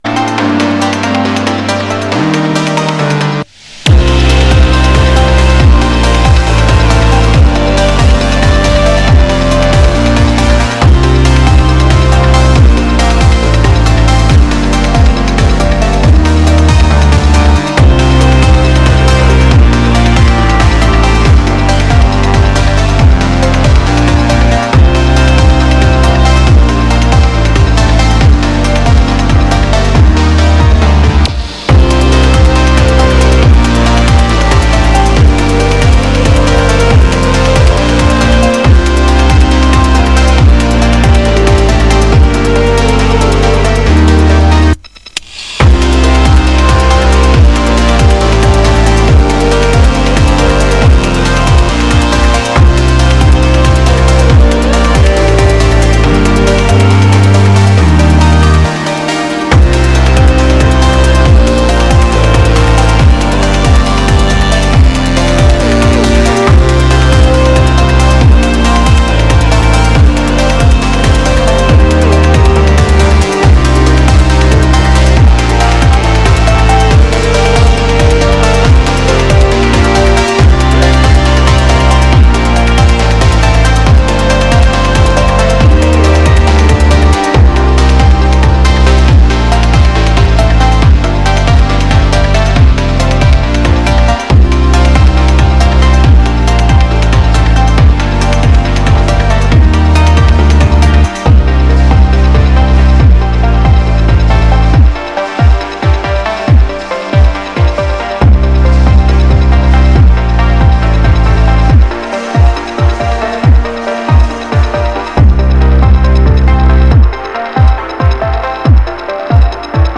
Ambient Techno / Dance Electronica / IDM